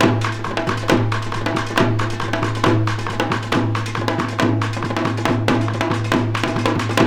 KOREA PERC 3.wav